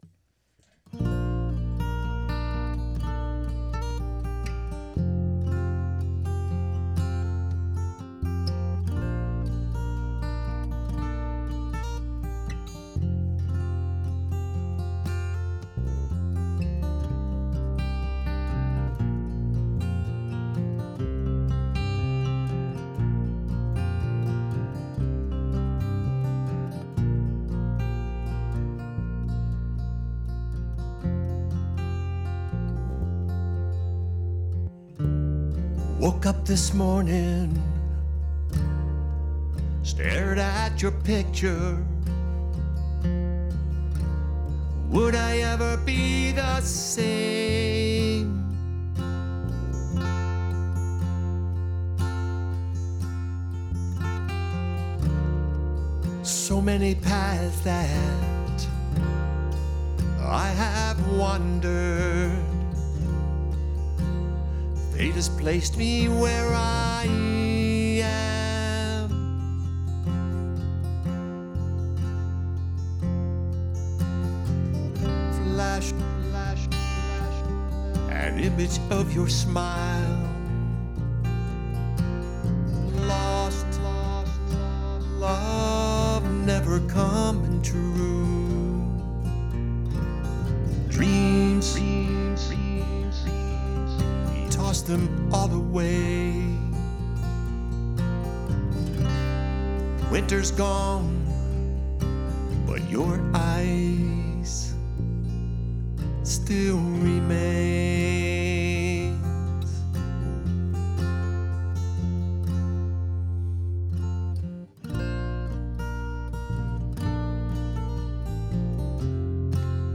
Recorded at Mohawk Studio.